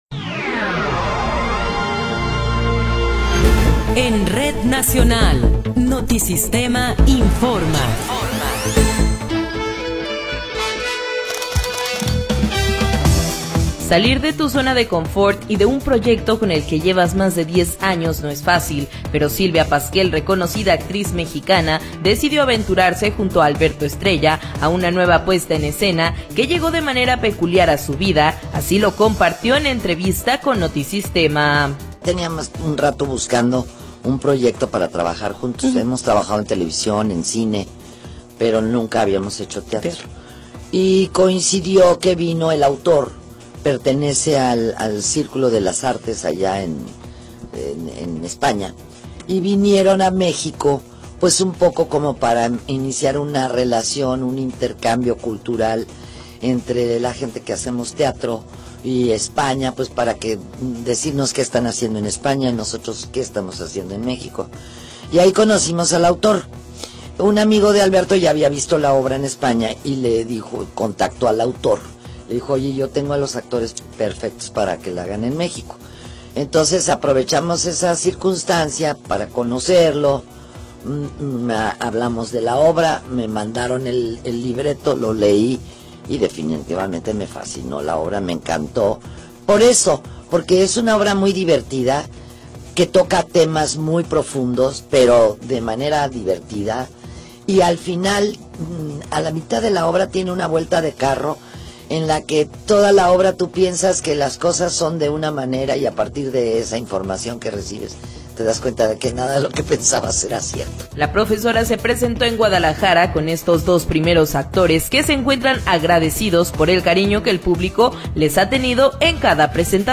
Noticiero 19 hrs. – 8 de Marzo de 2026